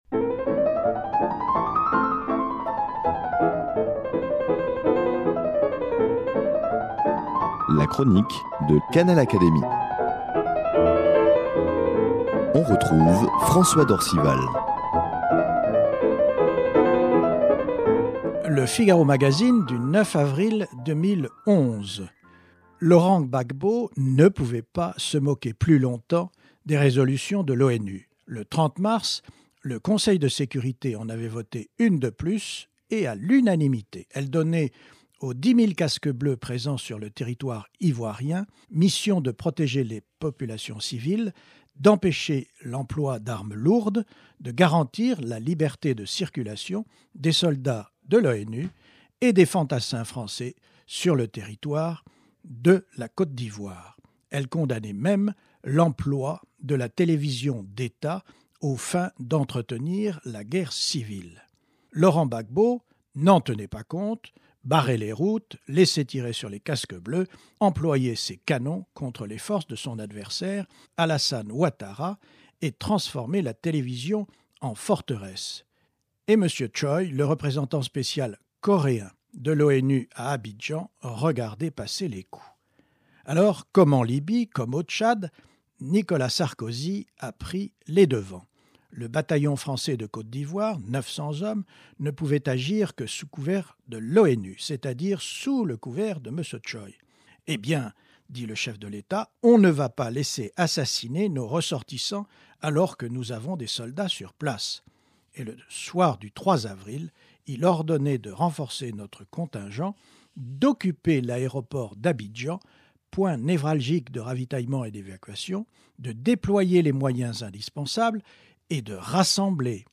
Elle est reprise ici par son auteur, avec l’aimable autorisation de l’hebdomadaire.